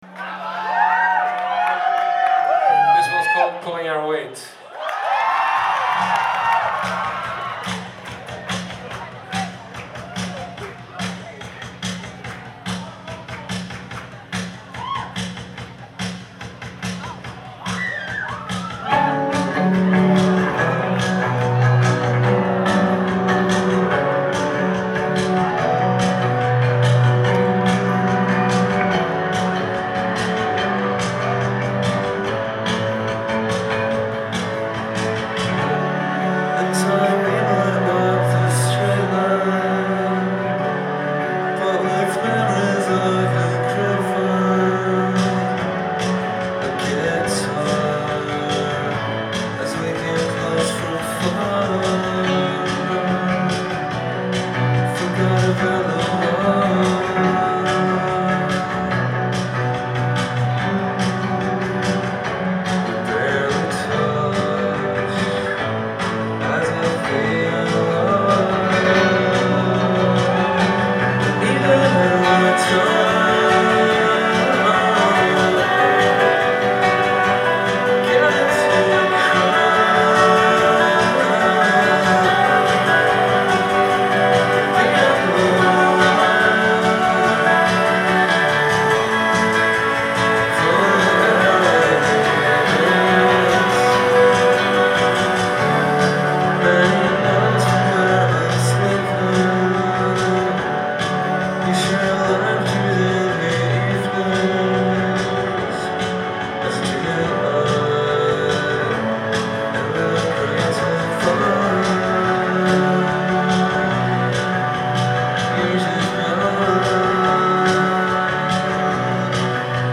Live at the Bell House
in Brooklyn, NY